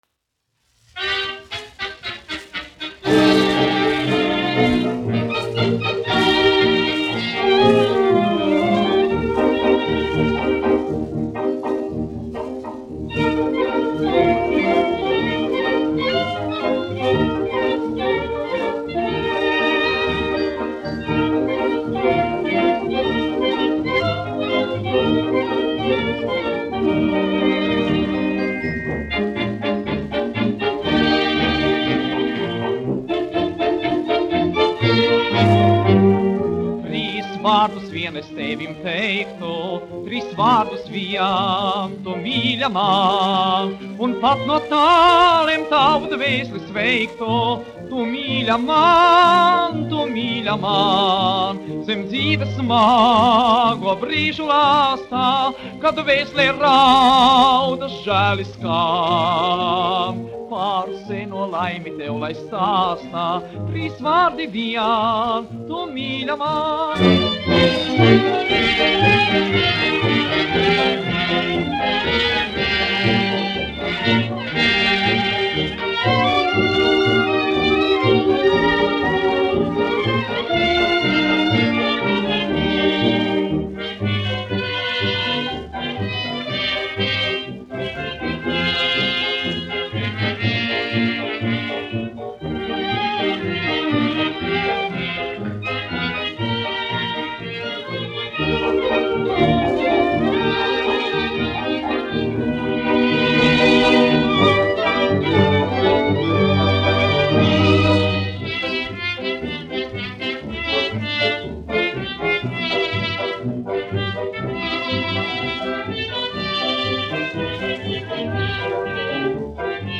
1 skpl. : analogs, 78 apgr/min, mono ; 25 cm
Fokstroti
Populārā mūzika
Skaņuplate